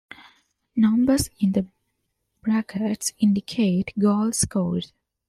Pronounced as (IPA) /ˈbɹækɪts/